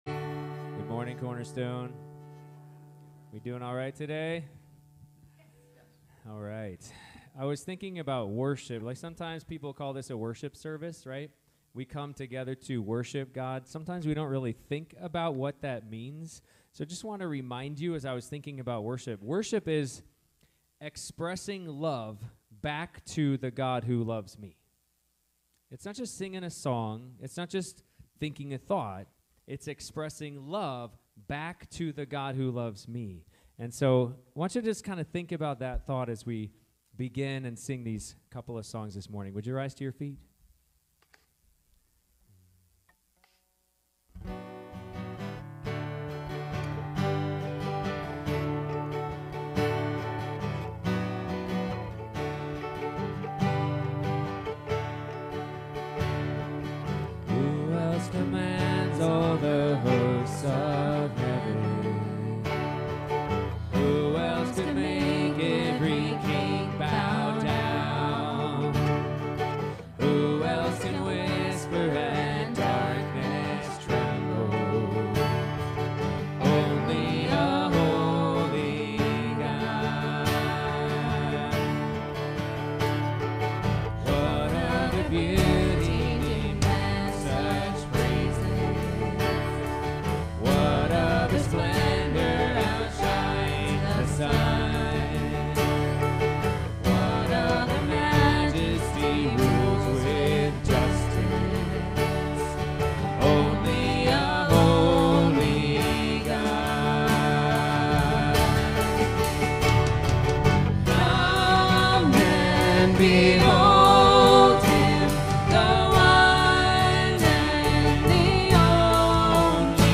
Passage: Ephesians 5:1-2 Service Type: Sunday Morning